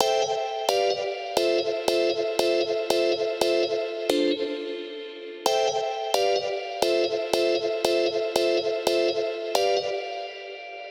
Carousel88bpm.wav